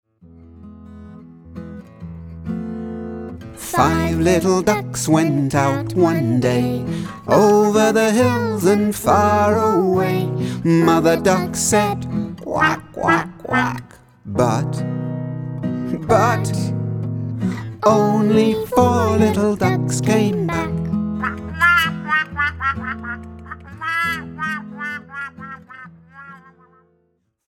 Sing and play in English